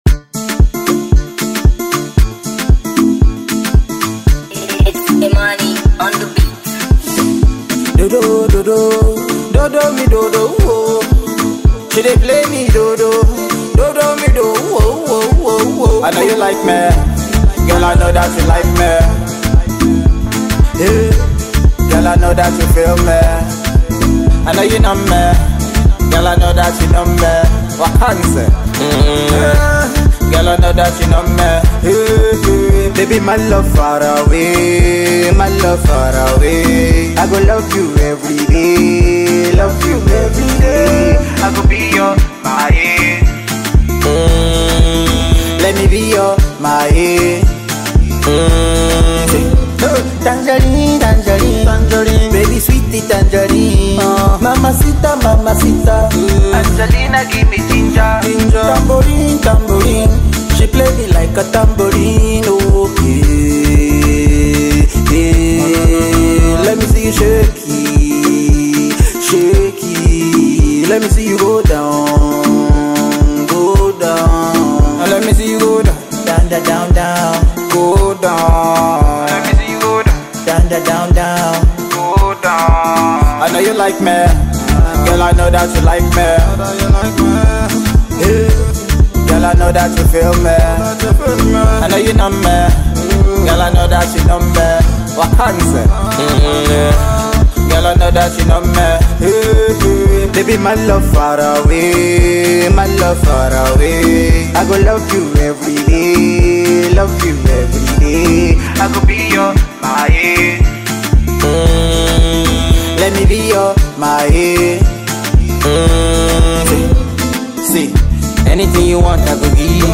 Afrobeat singer